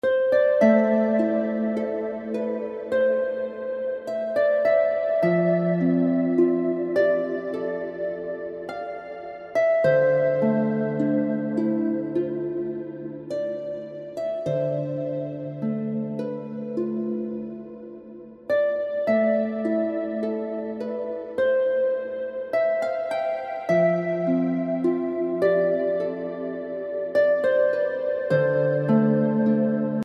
Heavenly Harp Music Tag